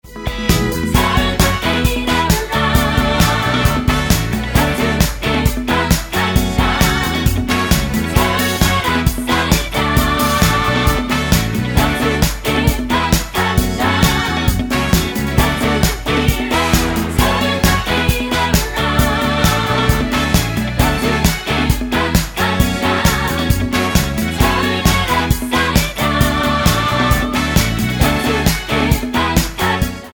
Tonart:F#m-Gm mit Chor
Die besten Playbacks Instrumentals und Karaoke Versionen .